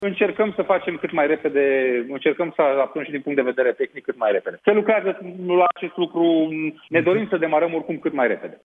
Secretarul de Stat în ministerul Sănătății, Andrei Baciu, spune la Europa FM că se lucrează acum la mai multe probleme de ordin tehnic: